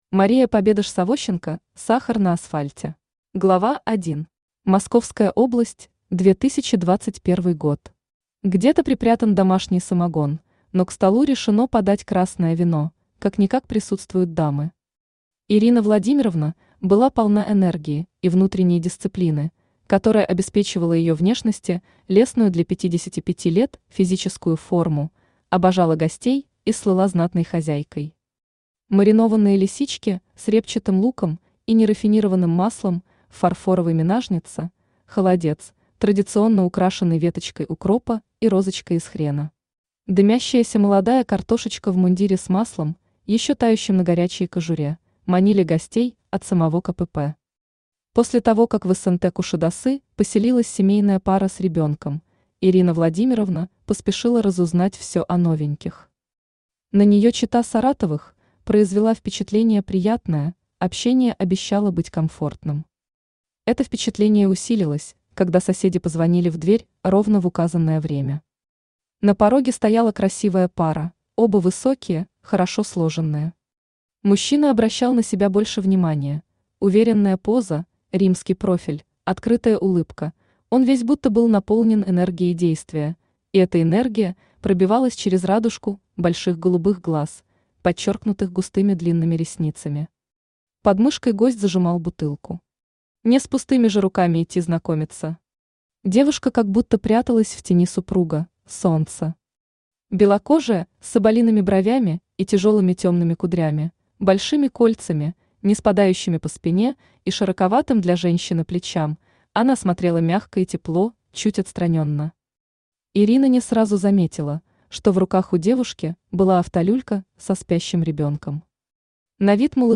Аудиокнига Сахар на асфальте | Библиотека аудиокниг
Aудиокнига Сахар на асфальте Автор Мария Победаш-Савощенко Читает аудиокнигу Авточтец ЛитРес.